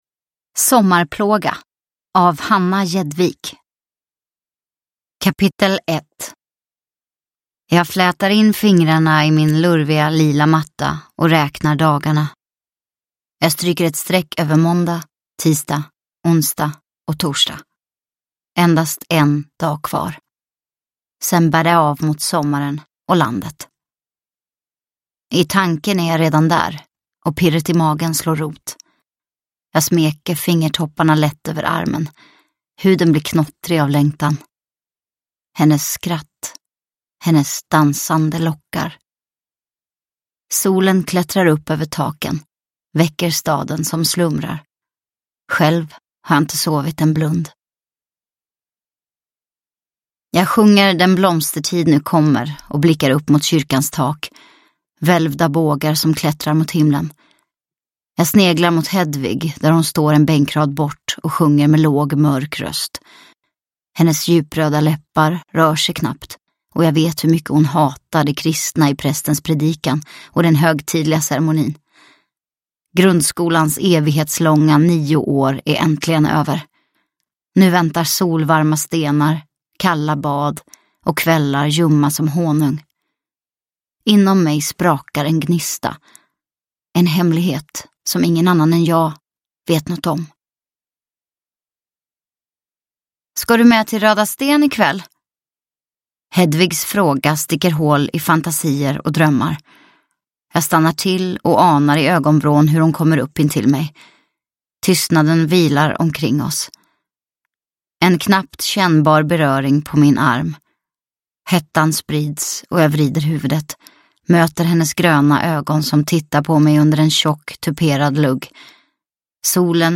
Sommarplåga – Ljudbok – Laddas ner